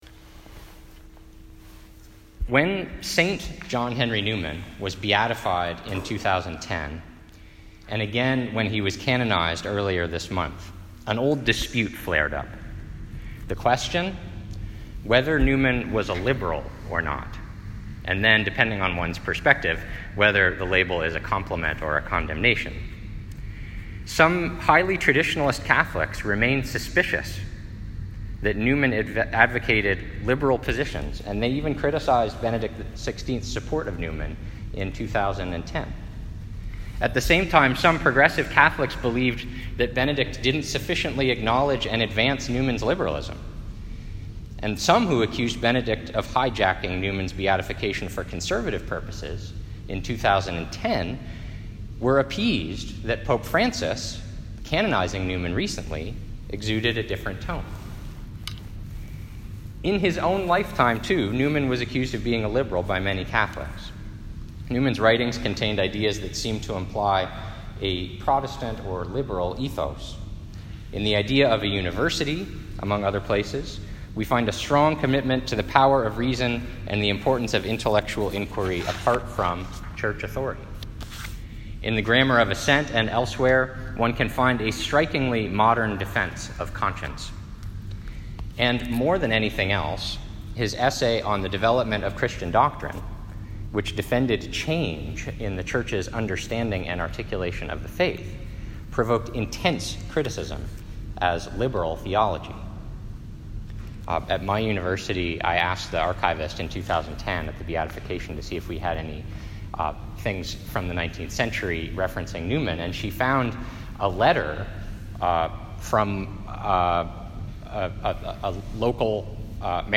This lecture was given at the University of Toronto on 22 October 2019.